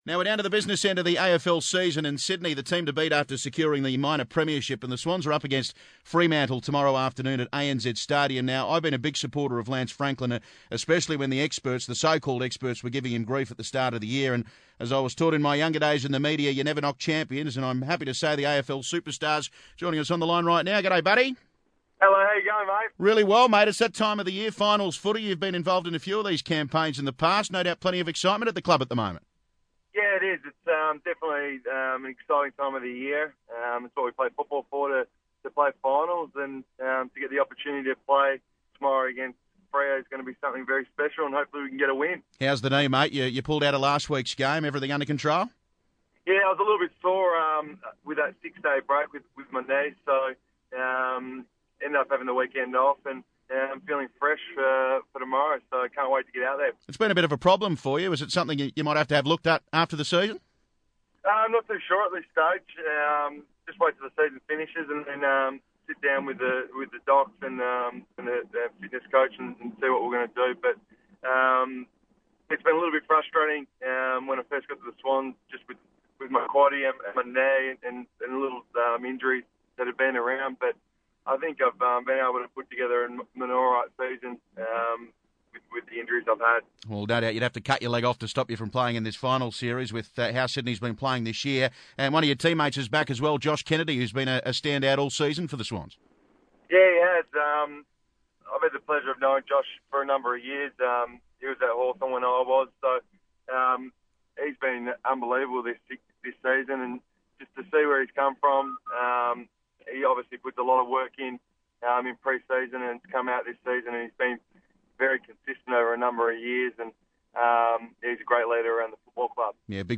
Swans forward Lance Franklin appeared on 2GB's Sportzone on Friday September 5, 2014